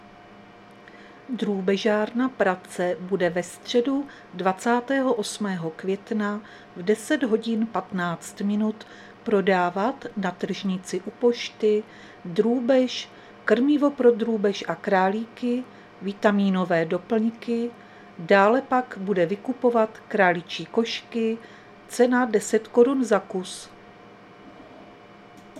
Záznam hlášení místního rozhlasu 27.5.2025
Zařazení: Rozhlas